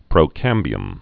(prō-kămbē-əm)